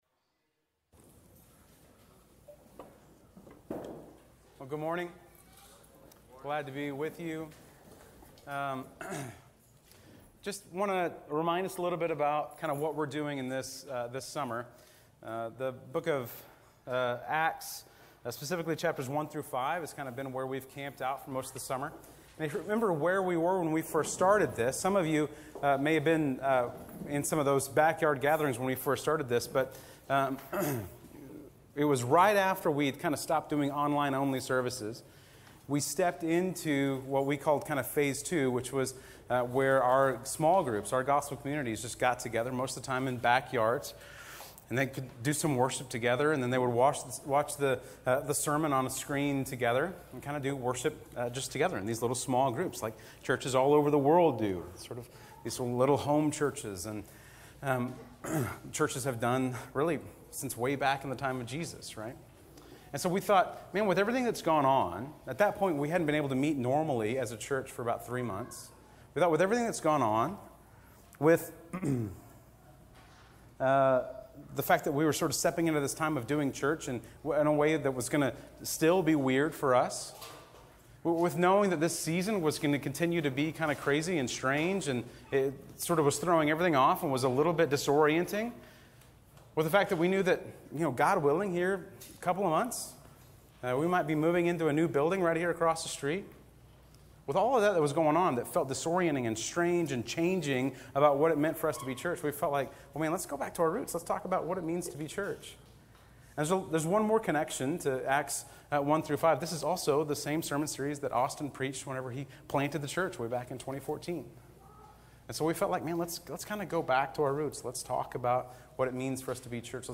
The Well's Aug 2nd Live Worship Gathering_3.mp3